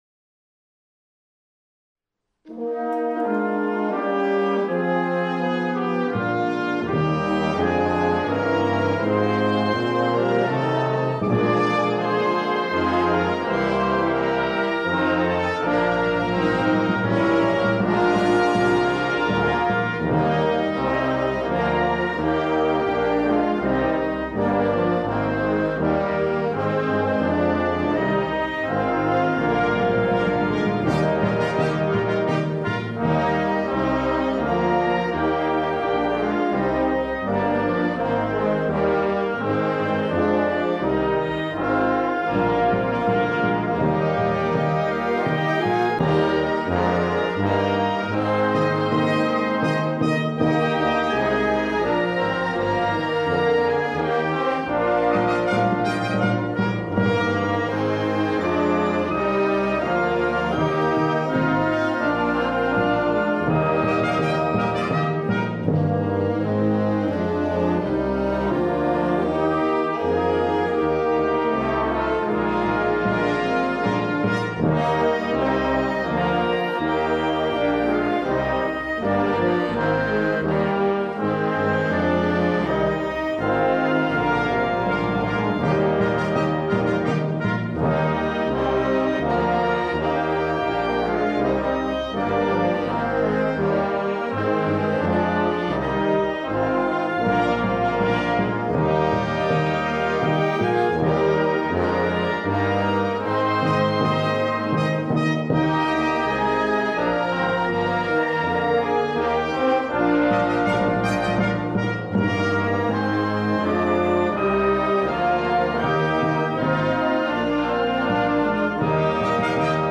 Volksgesang und Blasorchester.